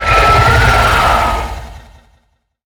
Sfx_creature_shadowleviathan_chitter_06.ogg